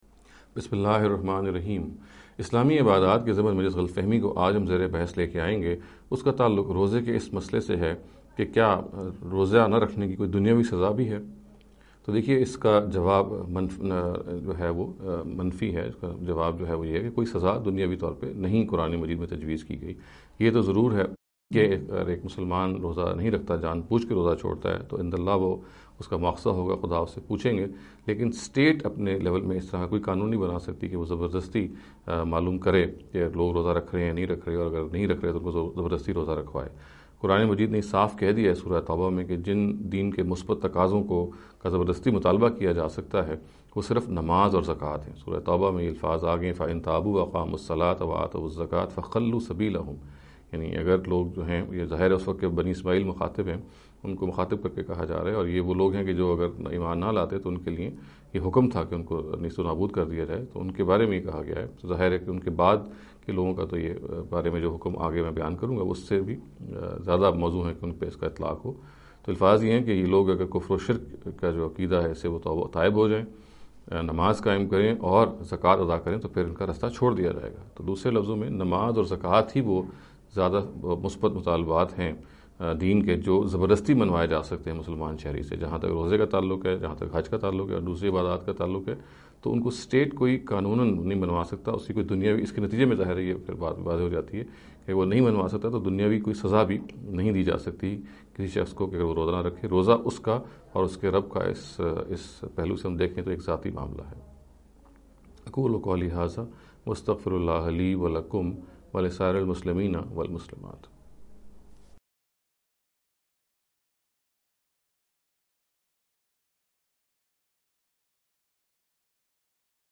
This lecture series will deal with some misconception regarding the Islamic Worship Ritual.